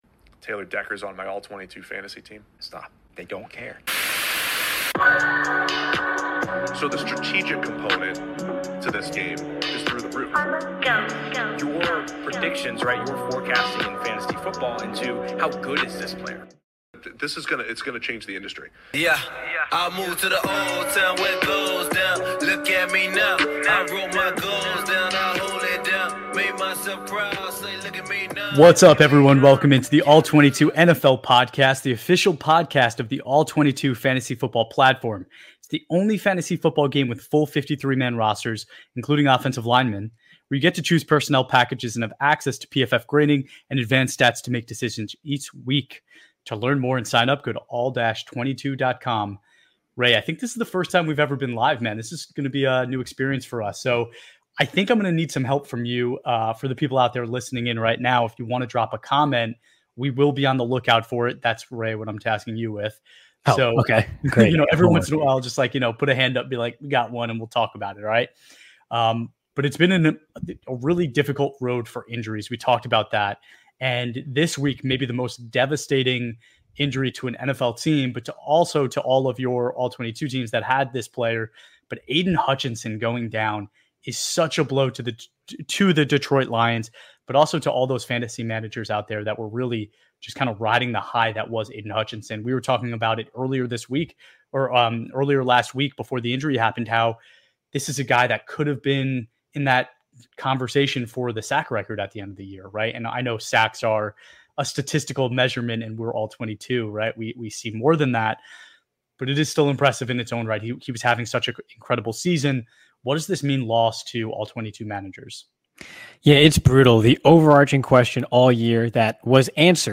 The All-22 guys went live to discuss the latest injury news, blockbuster trades, and everything you need to know for week 7 in the NFL.